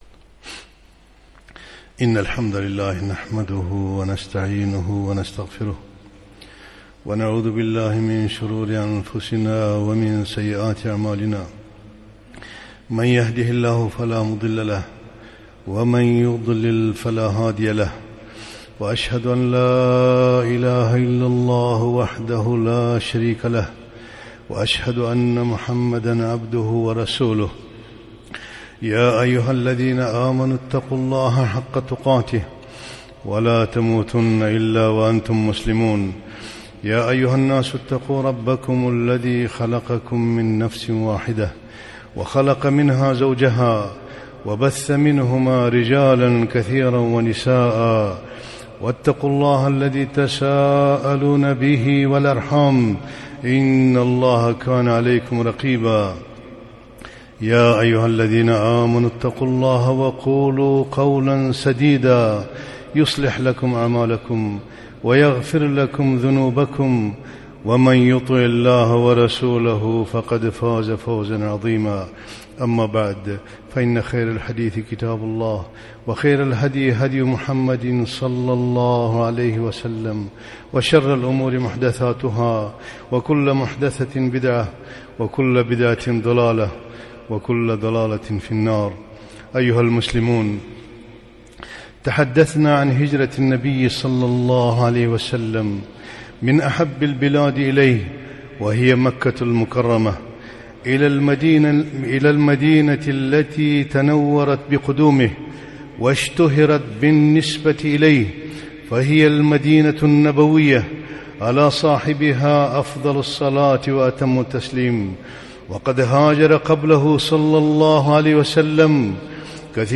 خطبة - أهمية الهجرتين